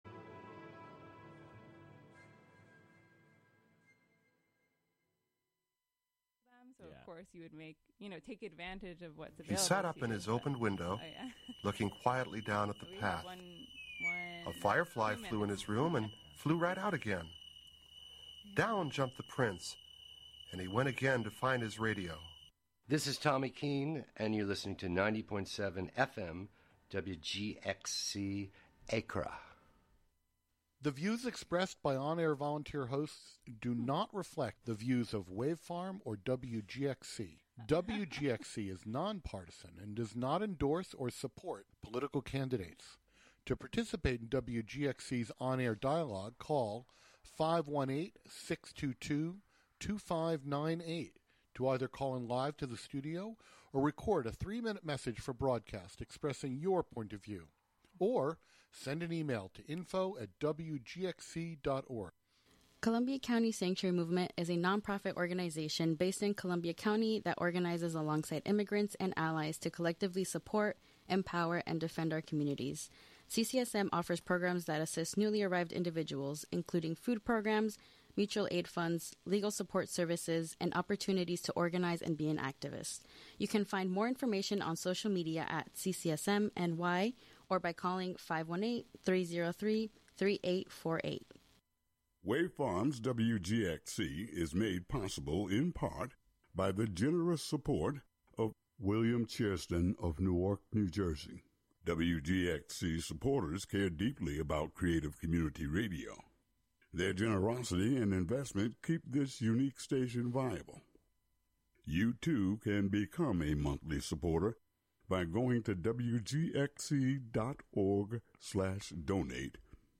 Wild Arc : Nov 03, 2025: 11am - 11:59 am In this show, we are joined by Wild Arc Farm.